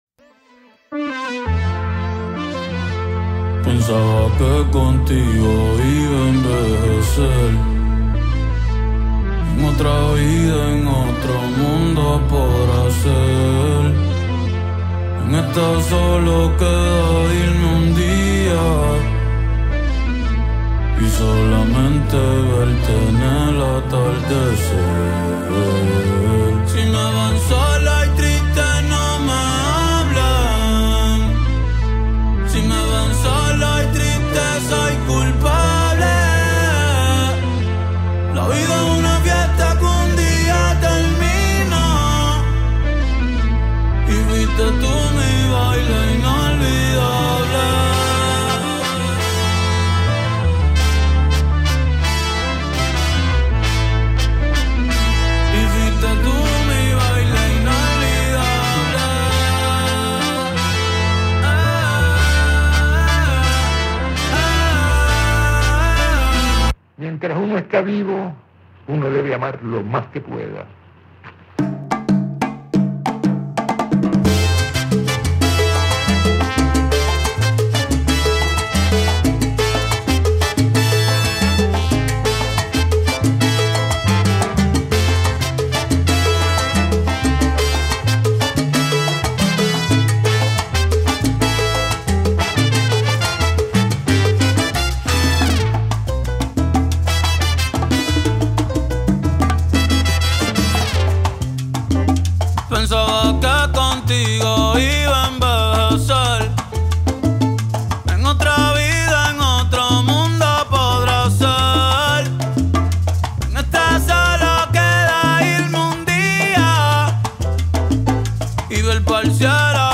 آهنگ‌های وایرال رگیتونی